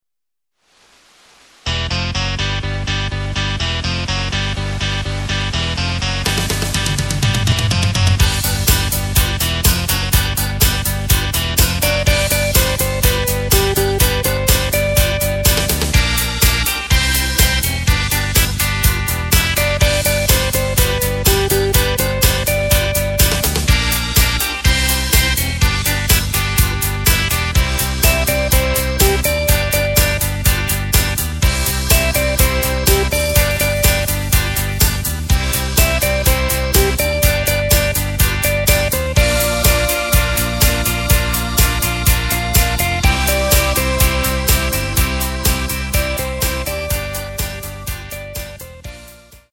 Takt:          4/4
Tempo:         124.00
Tonart:            G
Rock aus dem Jahr 1970!